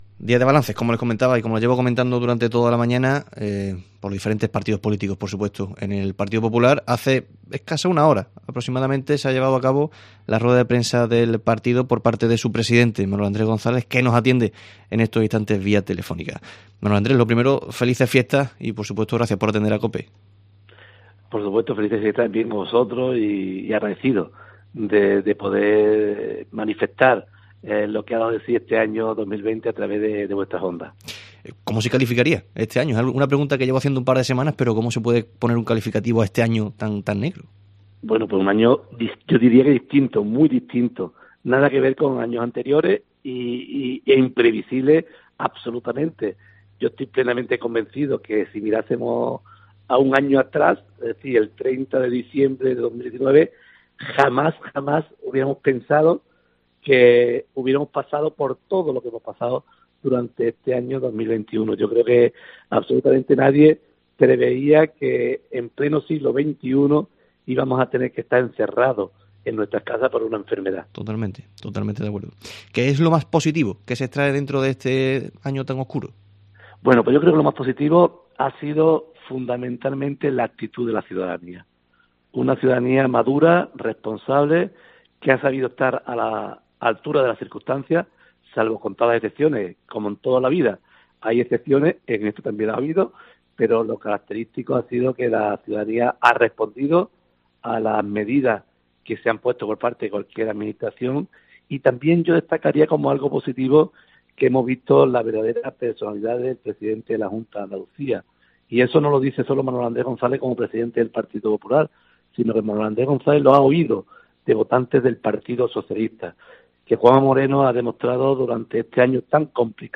En el Mediodía COPE Huelva de este miércoles 30 de diciembre nos ha atendido Manuel Andrés González, presidente del PP de Huelva, que ha analizado el 2020 y sus deseos para el 2021